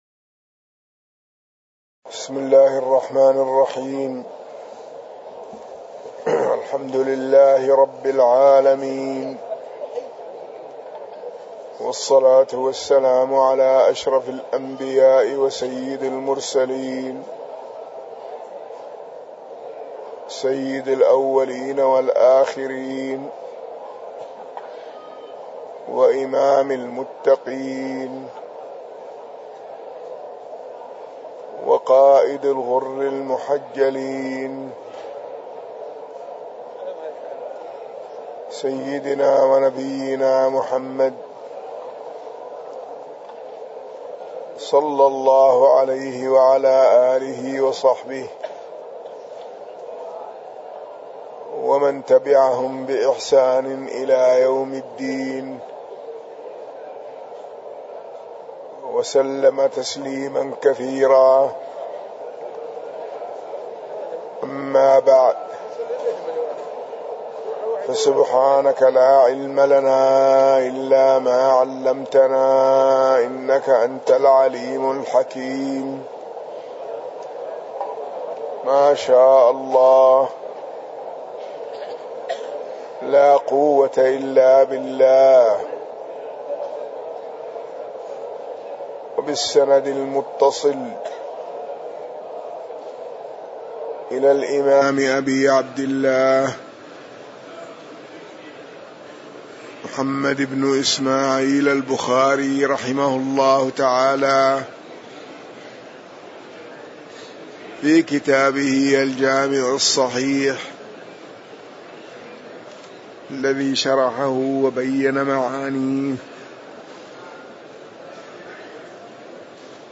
تاريخ النشر ٢ محرم ١٤٤٠ هـ المكان: المسجد النبوي الشيخ